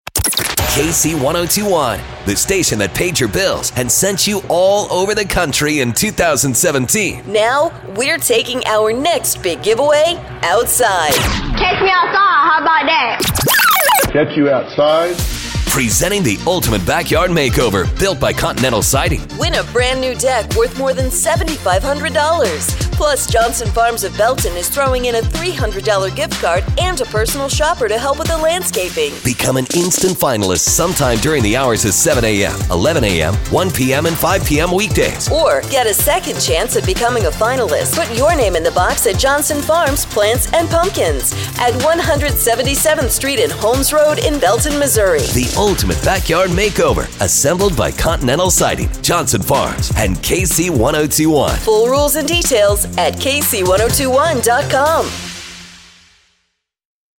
Promotional announcement for KCKC Backyard Makeover 2017.